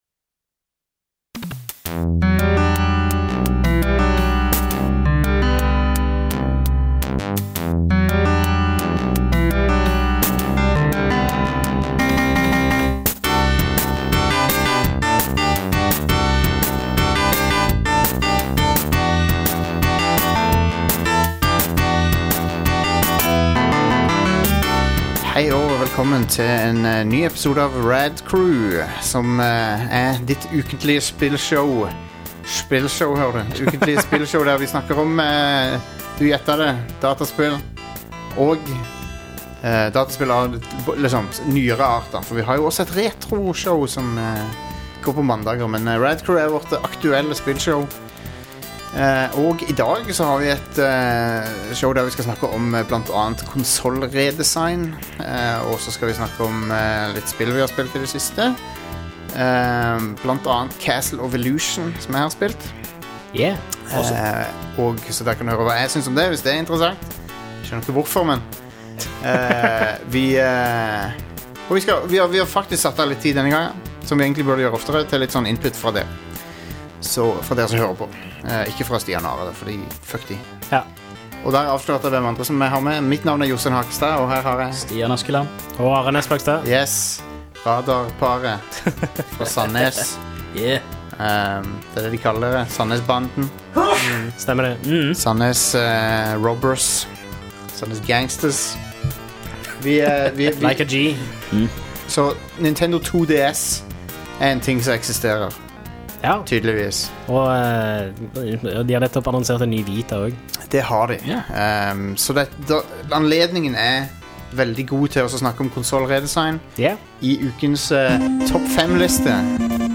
Midtveis får litt musikalsk pauseunderholdning og helt til slutt blir det naturligvis tid til feedback fra lytterne.